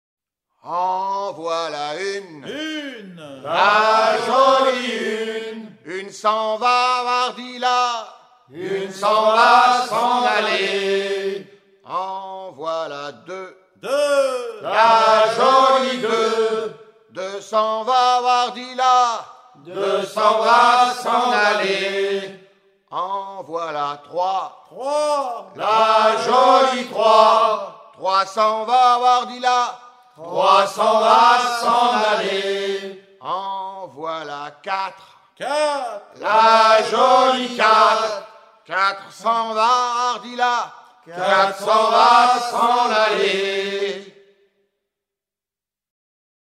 chant à embarquer la morue à la main
Genre énumérative
Pièce musicale éditée